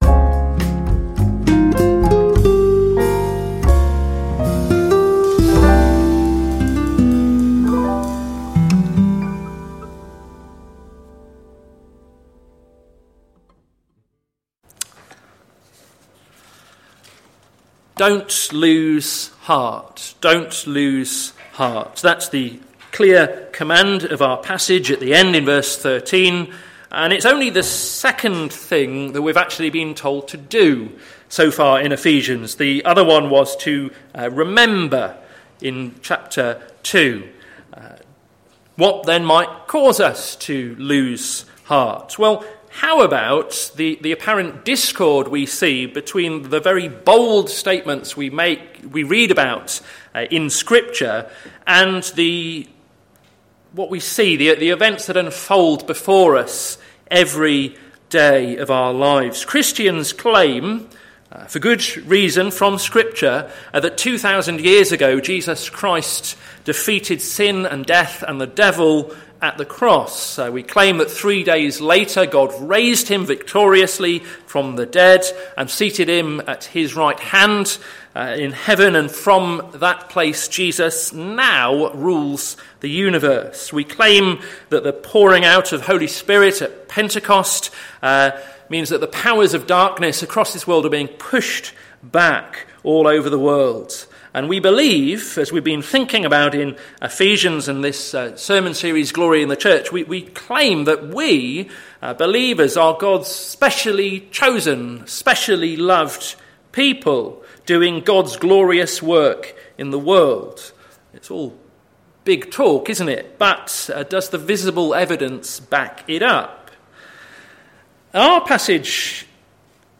Sermon Series - Glory in the Church - plfc (Pound Lane Free Church, Isleham, Cambridgeshire)